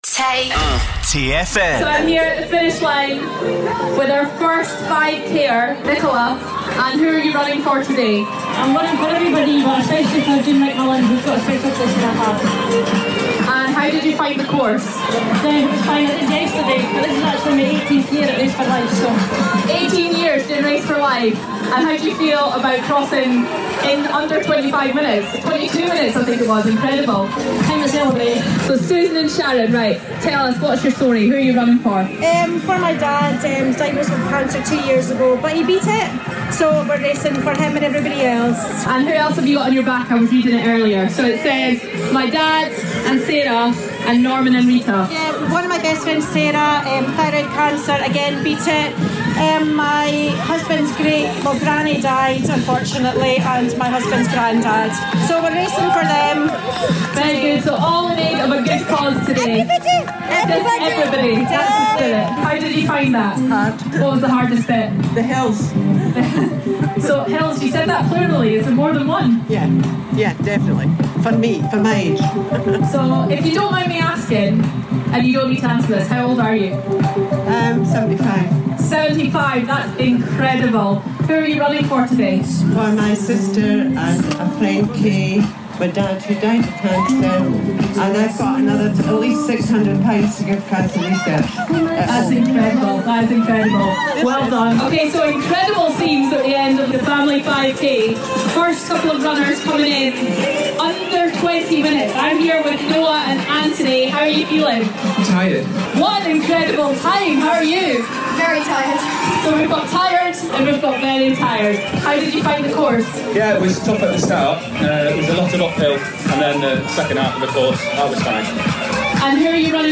Dundee's Race For Life Finish line.